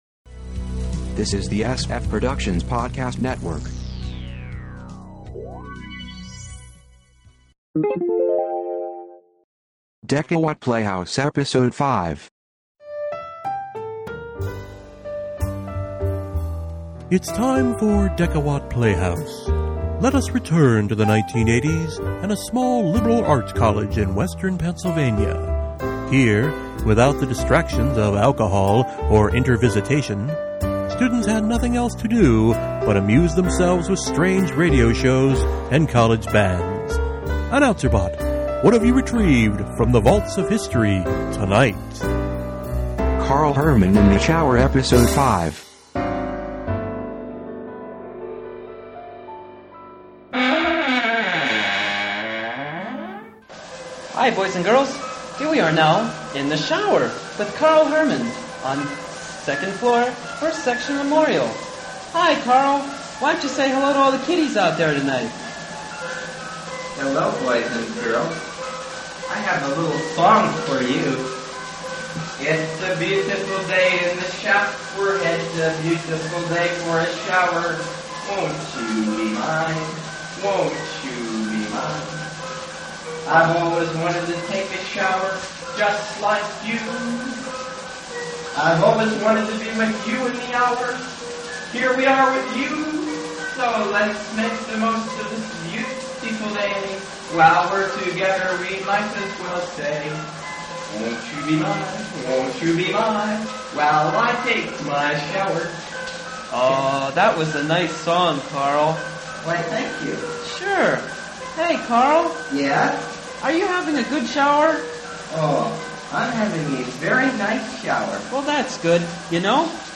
On to episode five, plus a promo!